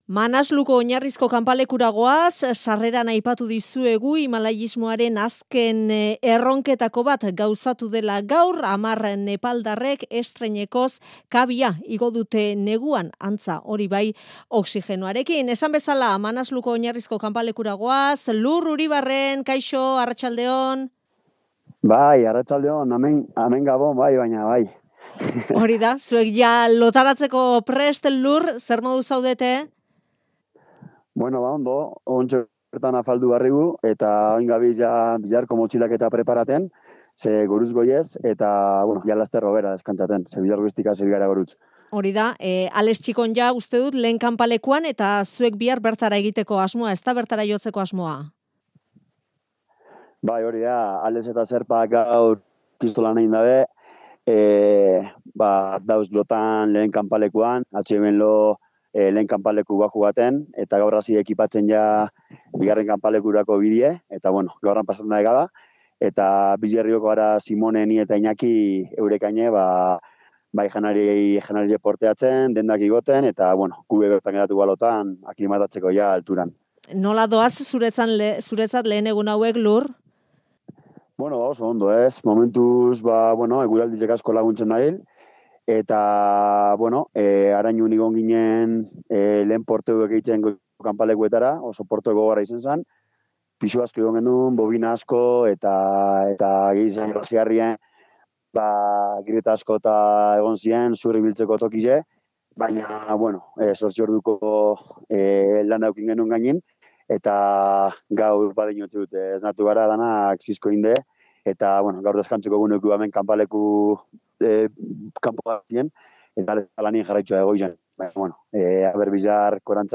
zuzenean Manasluko kanpaleku nagusitik